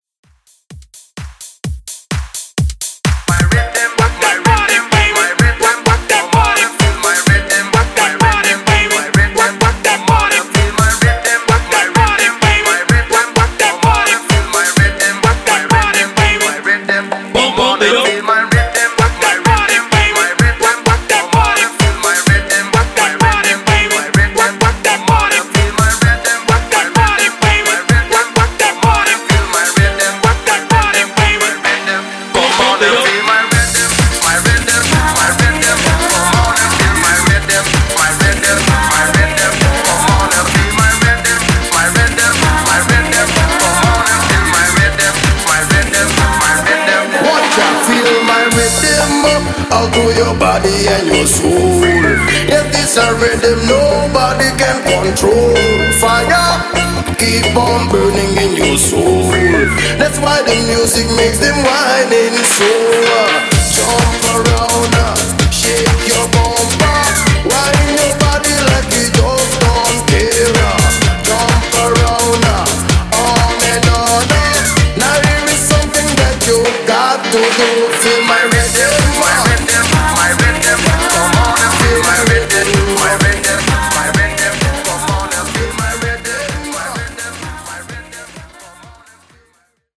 Extended Re-Drum)Date Added